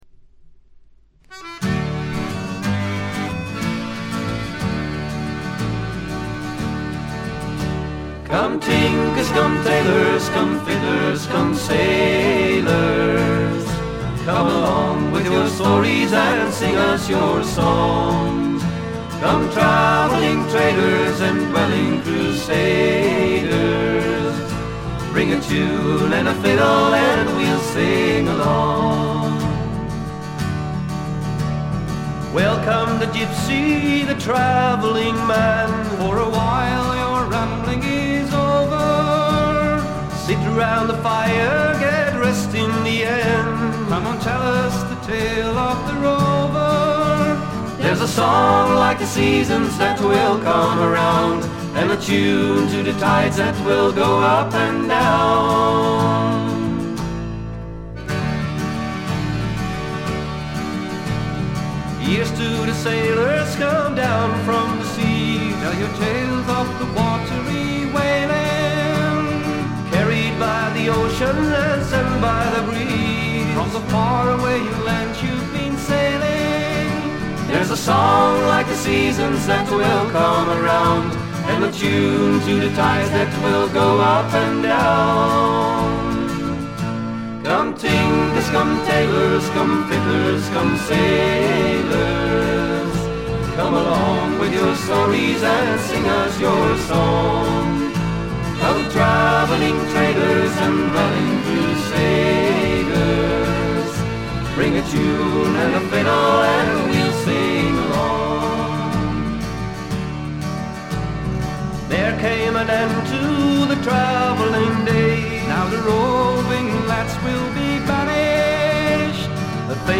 1stの木漏れ日フォーク的な穏やかさ、暖かさを残しながらも、メリハリの利いた演奏と構成で完成度がぐんと上がっています。
ジェントルな男性ヴォーカル、可憐で美しい女性ヴォーカル（ソロパートが少ないのがちょっと不満）、見事なコーラスワーク。
Acoustic Guitar, Vocals
Accordion
Bass Guitar
Flute
Oboe
Violin